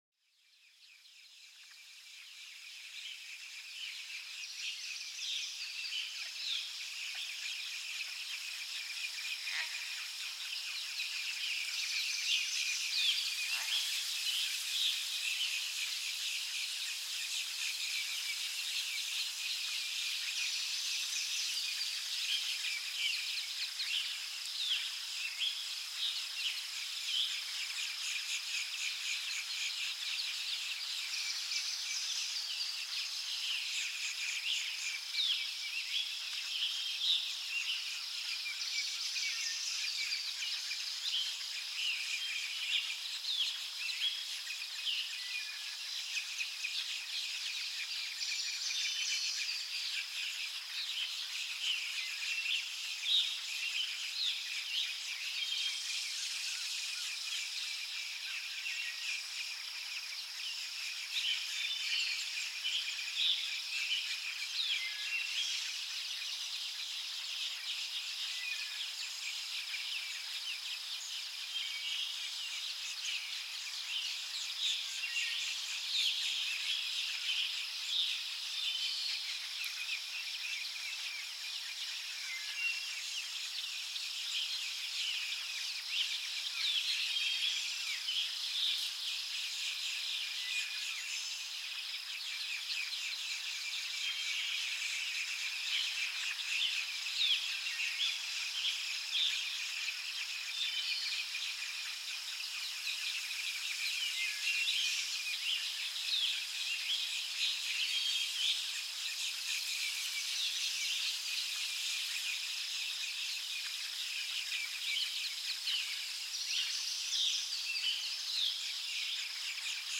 LA RELAXATION PAR LES SONS DE LA NATURE